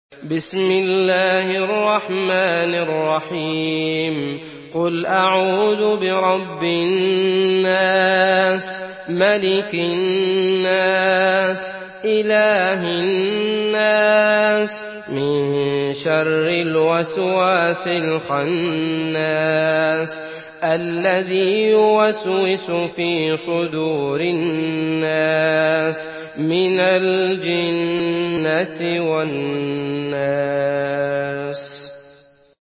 مرتل